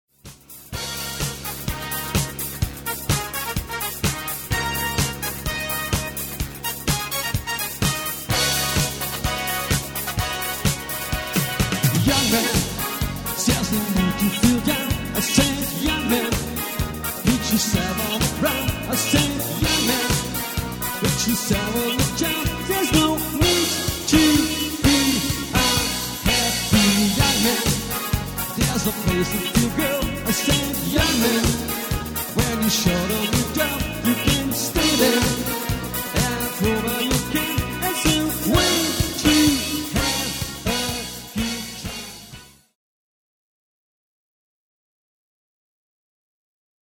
junge dynamische Band mit Sängerin für Hochzeiten
• Cover 2